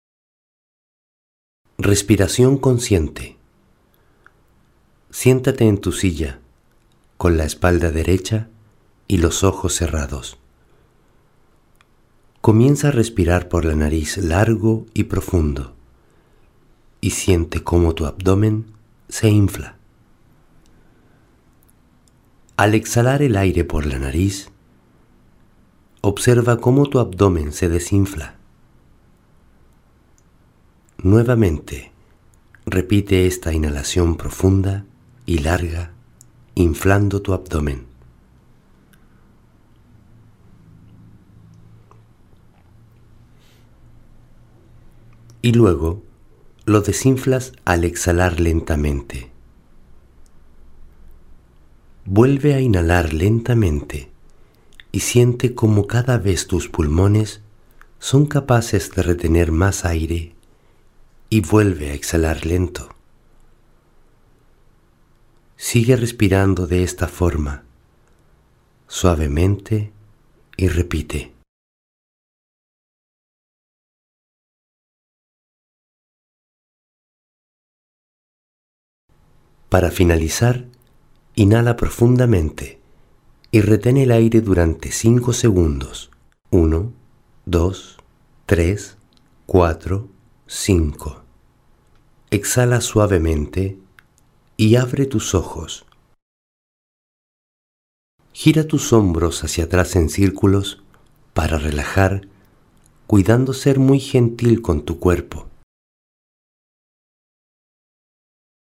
Melodía.